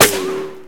ricochet sounds